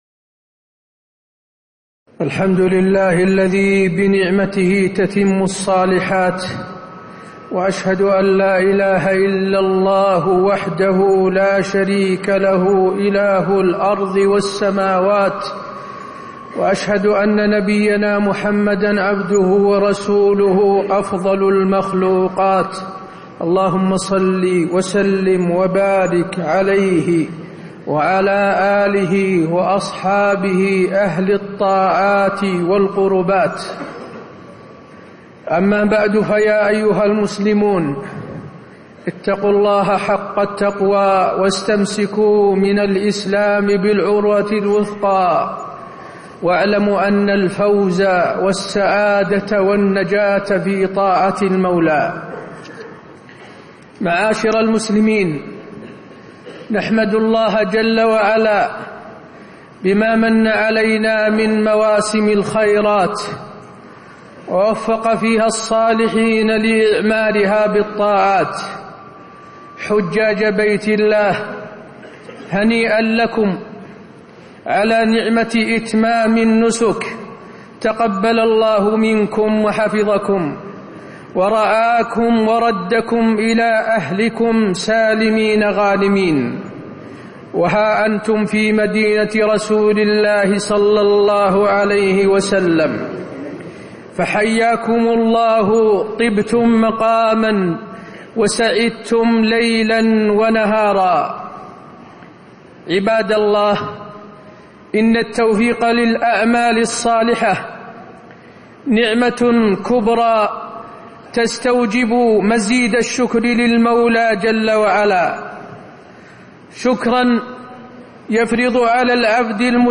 تاريخ النشر ١٩ ذو الحجة ١٤٣٦ هـ المكان: المسجد النبوي الشيخ: فضيلة الشيخ د. حسين بن عبدالعزيز آل الشيخ فضيلة الشيخ د. حسين بن عبدالعزيز آل الشيخ نصيحة إلى شباب التفجير